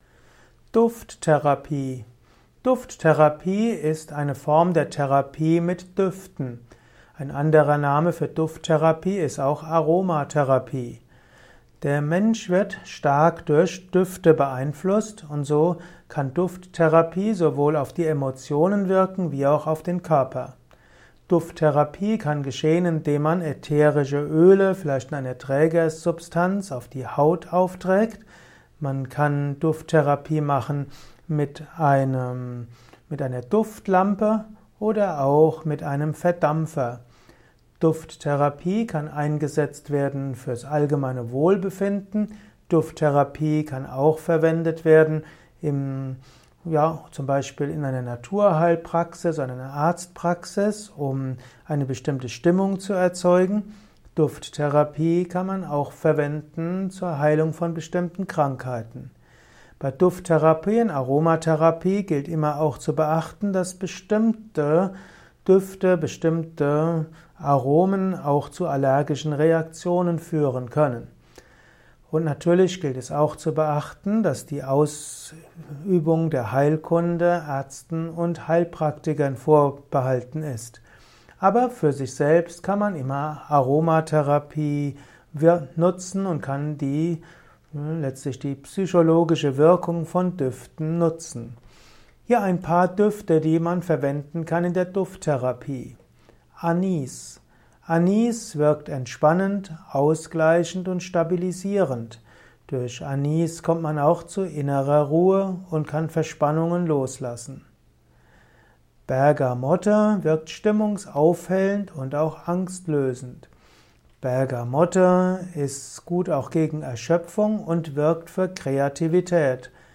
Vortragsaudio rund um das Thema Duft-Therapie. Erfahre einiges zum Thema Duft-Therapie in diesem kurzen Improvisations-Vortrag.